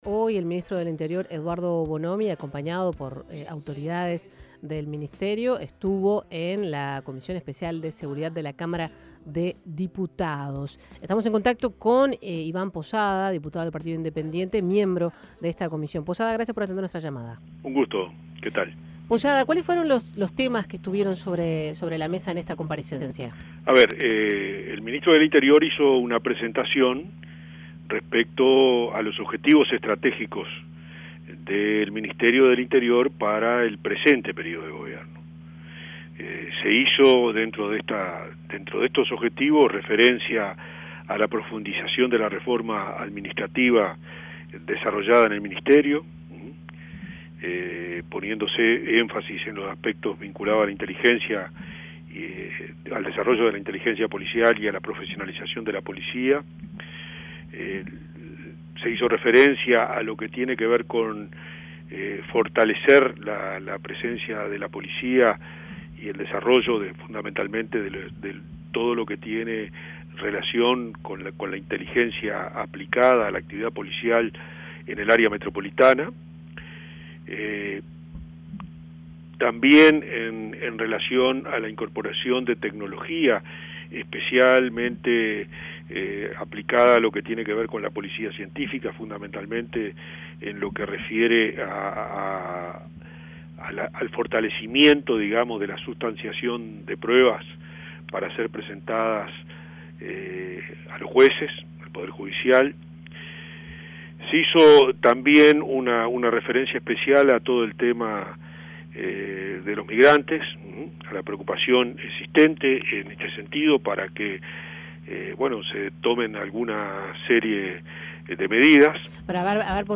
El Ministerio del Interior envió un proyecto de ley al parlamento para que las cárceles y el Instituto Nacional de Rehabilitación (INR) pasen a funcionar como órganos descentralizados según dijo el diputado del Partido Independiente, Ivan Posada, a 810 Vivo